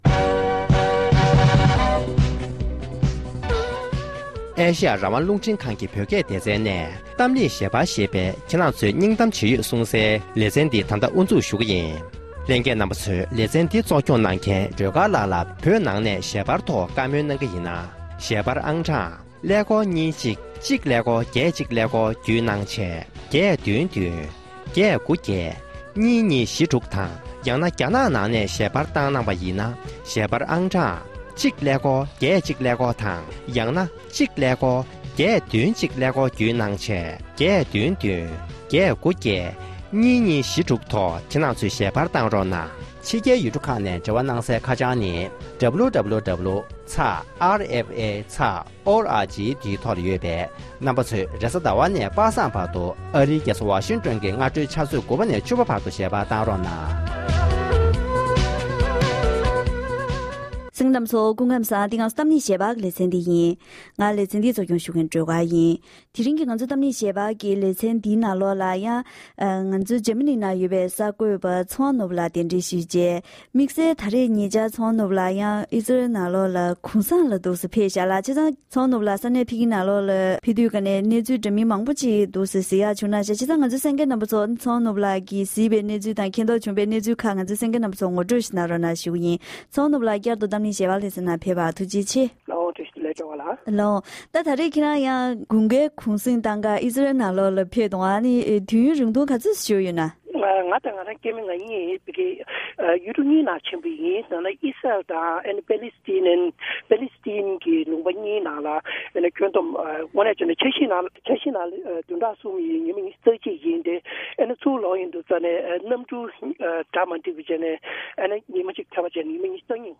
ཉེ་ཆར་ཨིཟ་རཡིལ་ནང་བསྐྱོད་མཁན་བོད་མི་ཞིག་དང་ལྷན་དུ་ཡུལ་དེའི་ངོ་སྤྲོད་སྐོར་གླེང་བ།